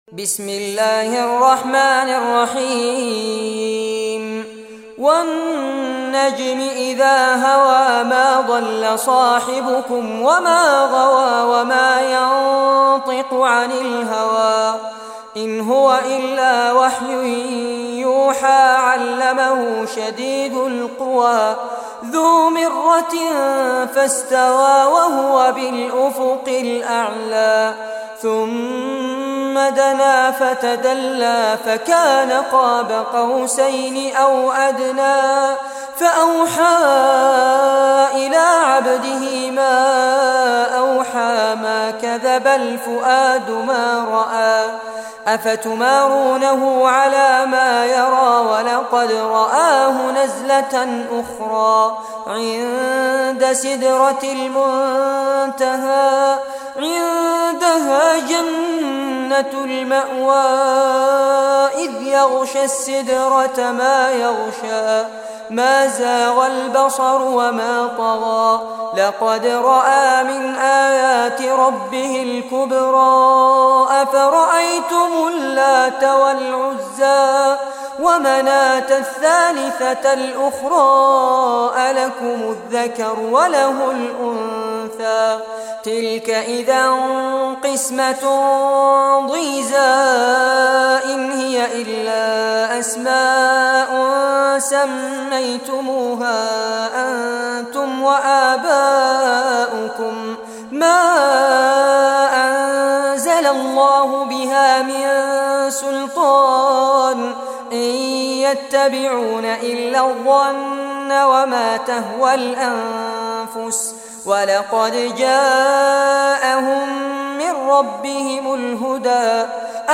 Surah An-Najm Recitation